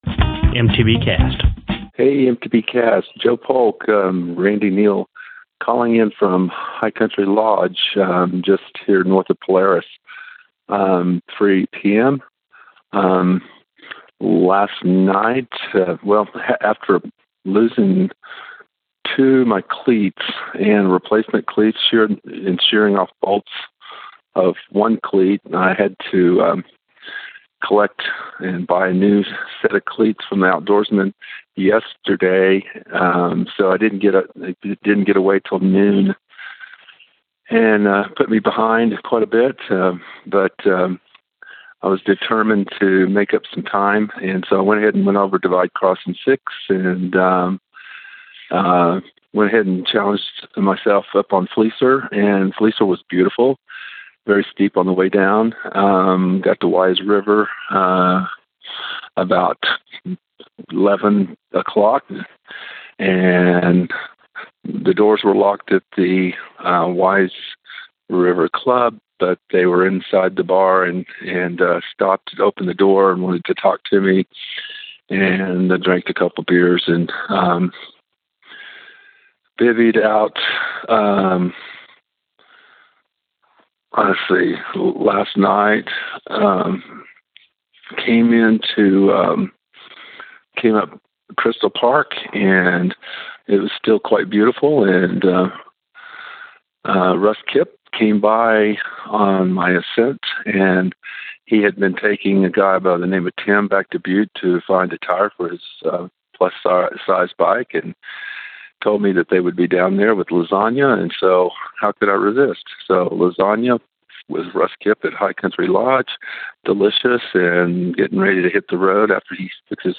Posted in Calls , TD17 Tagged bikepacking , calls , cycling , MTBCast , TD17 , ultrasport permalink